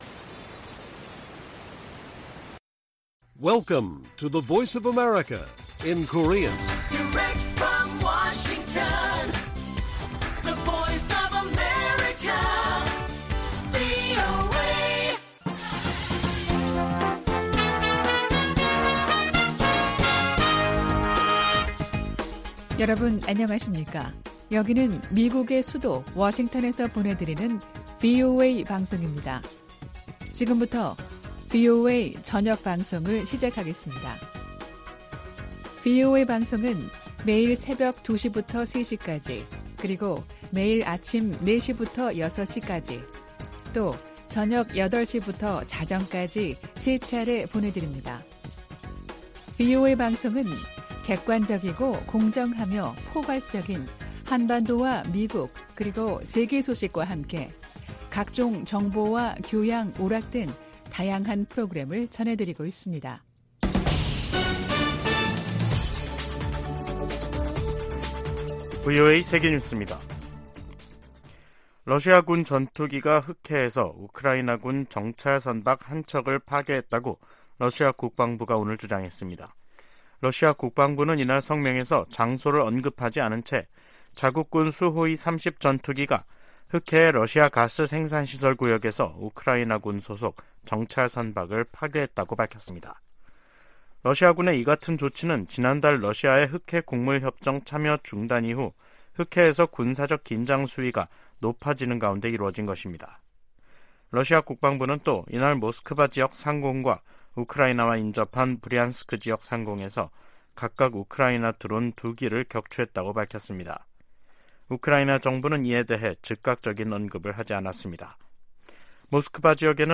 VOA 한국어 간판 뉴스 프로그램 '뉴스 투데이', 2023년 8월 22일 1부 방송입니다. 북한이 실패 3개월만에 군사정찰위성을 다시 발사하겠다고 예고했습니다. 백악관 국가안보회의(NSC) 인도태평양 조정관은 미한일 3국 협력이 위중해진 역내 안보를 지키기 위한 노력의 일환이라고 말했습니다. 6차례에 걸쳐 보내드리는 기획특집 [미한일 정상회의 결산] 첫 시간에 한층 격상된 3국 안보 협력 부분을 살펴봅니다.